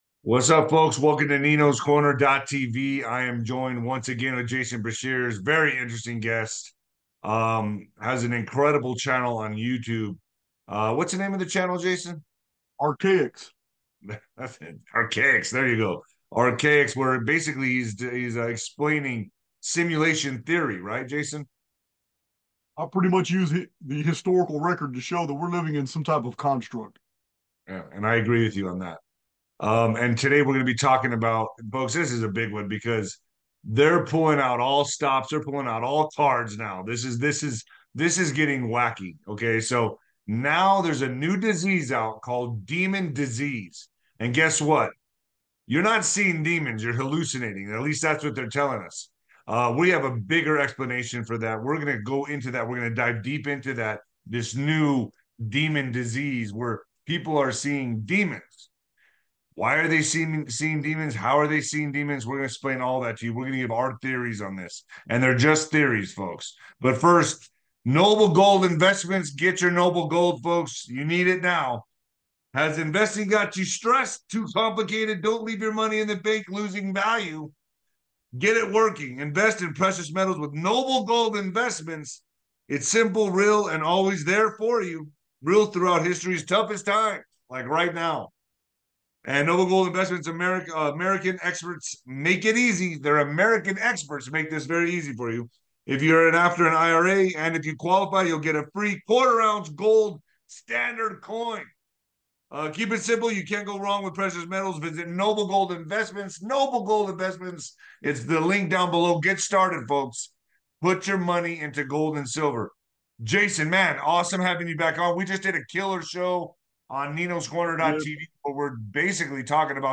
Tune in for a mind-bending conversation!